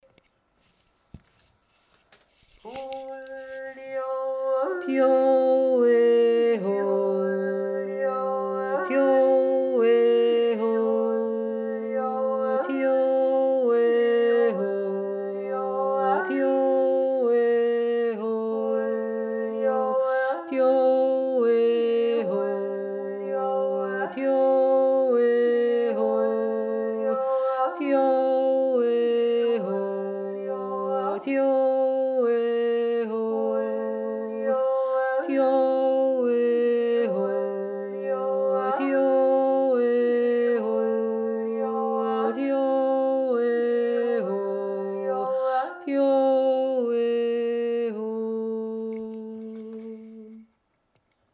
unser Jodelmantra